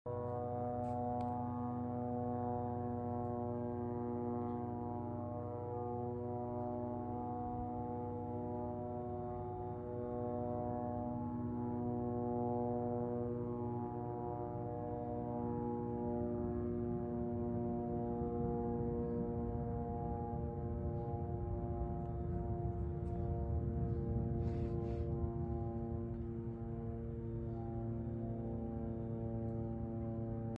The sound of drones is sound effects free download
The sound of drones is very, very annoying